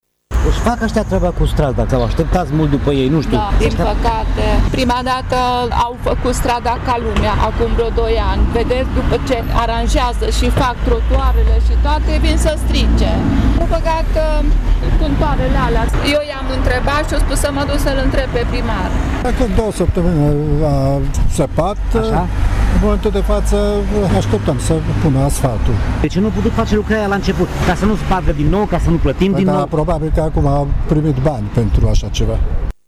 Tîrgumureșenii sunt nemulțumiți că, după ce trotuarele li s-au asfaltat, au fost sparte din nou pentru montarea unor contoare la utilități: